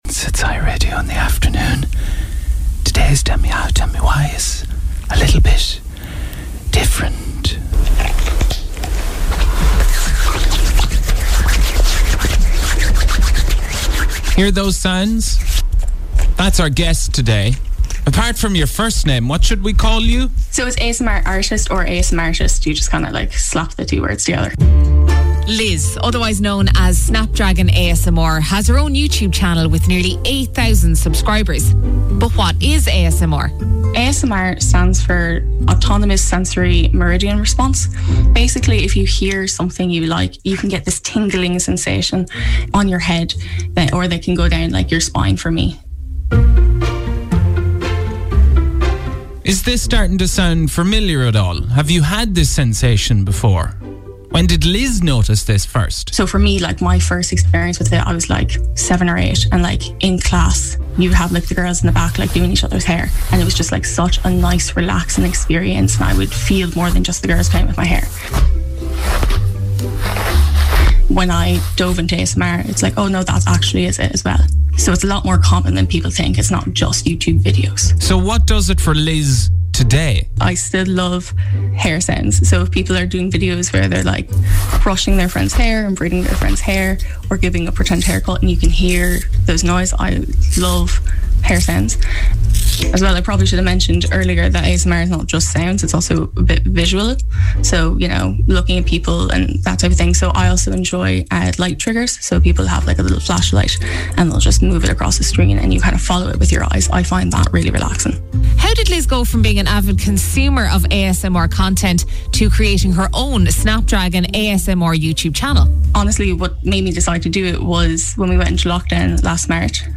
ATB X Topic x A7S Interview - 10.04.2021